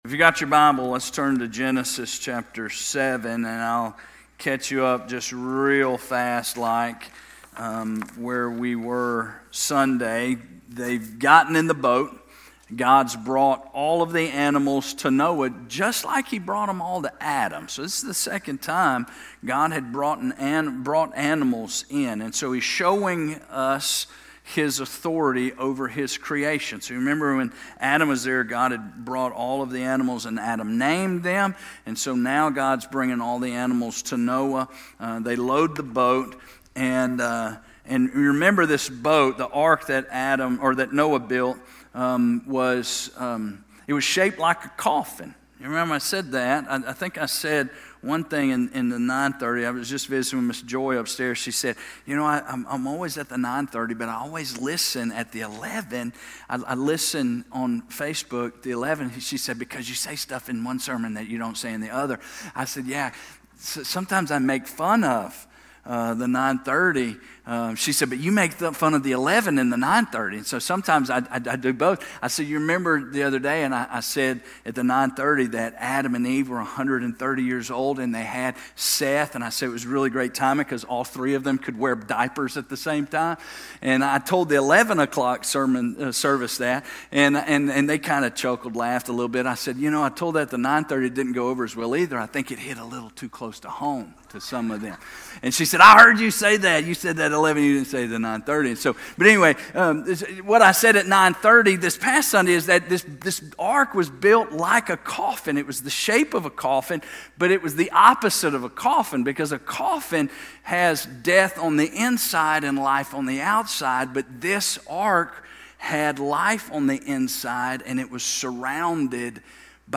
Genesis Service Type: Wednesday Night We're continuing to walk through Genesis.